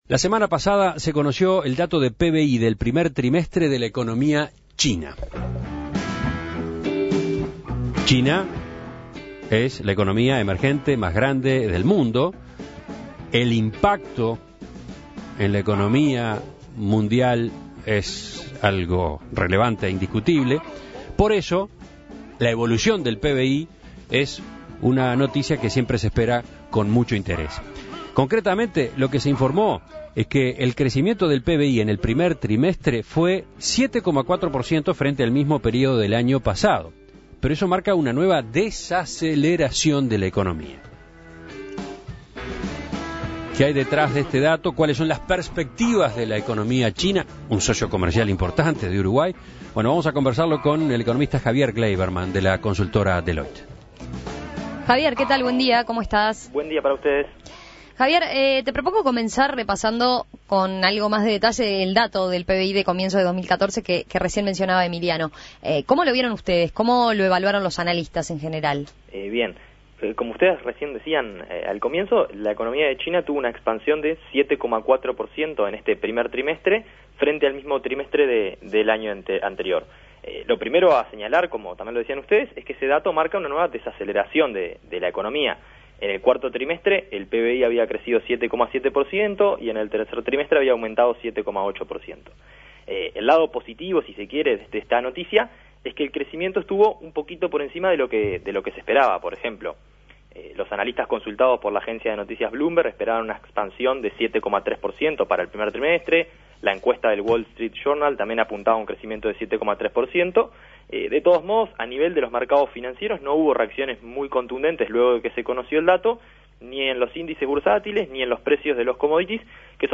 Análisis